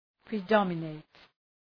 Προφορά
{prı’dɒmə,neıt}